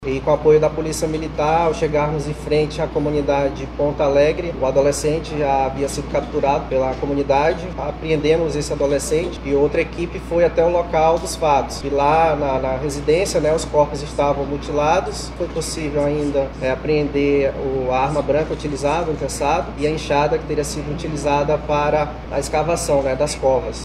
Em coletiva de imprensa realizada nesta segunda-feira (13)